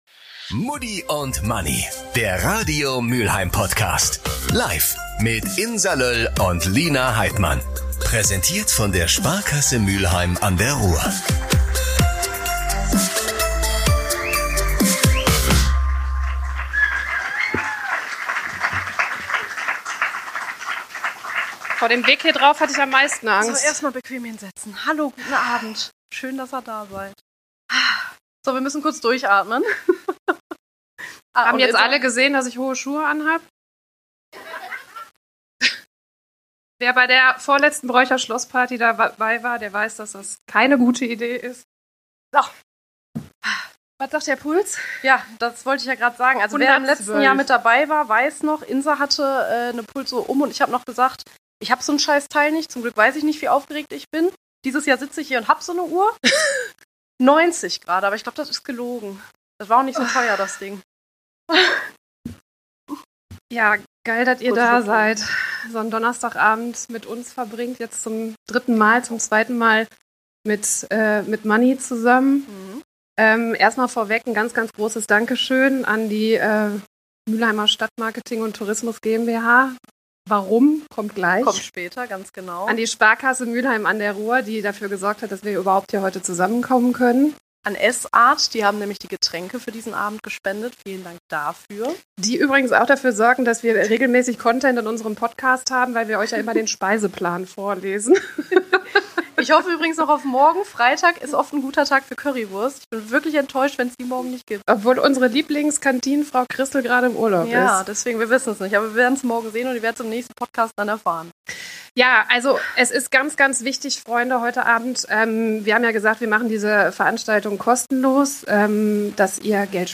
Hier kommt Teil 1 unseres Livepodcasts vom 27. November aus unserem Radio Zuhause - der Funke Mediengruppe in Essen. Danke für rund 1300 Euro Spenden, die ihr für unsere Aktion Lichtblicke gegeben habt.